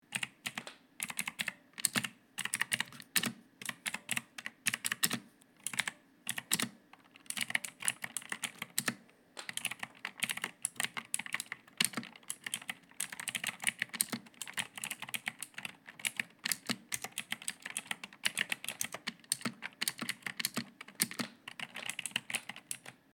Typing Keyboard